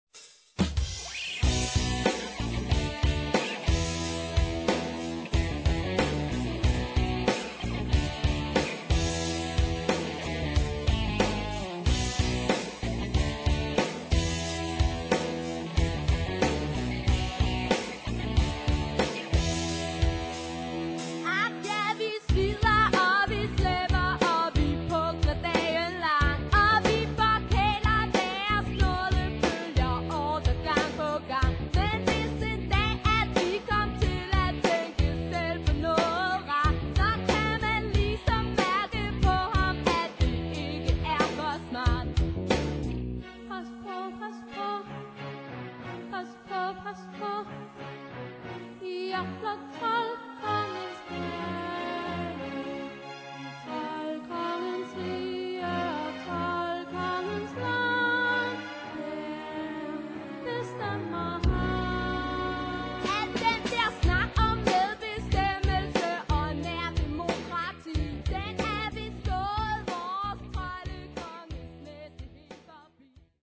Derudover hører vi en stribe smukke og fængende ørehængere.
Goblinge-rock